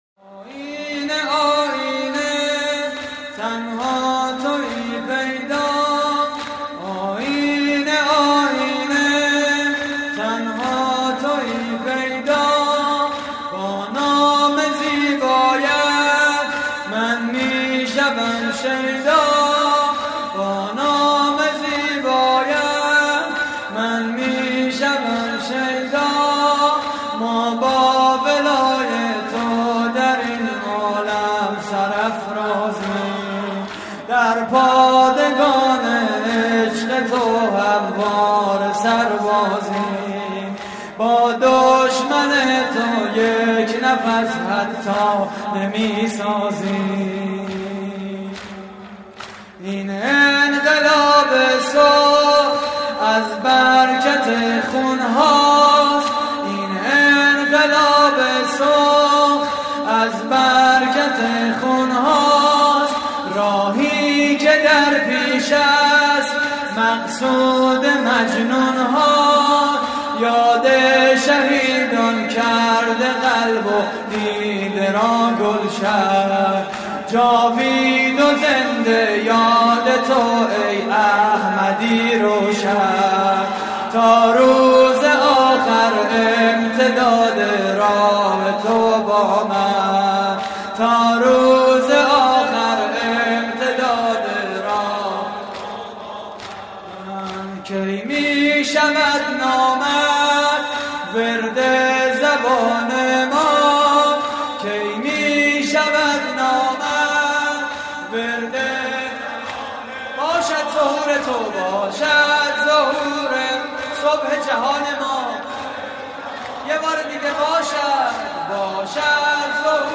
مراسم عزاداری شب دوم ماه محرم / هیئت الزهرا (س) – دانشگاه صنعتی شریف؛ 26 آبان 1391
صوت مراسم:
شور: تنها تویی پیدا؛ پخش آنلاین |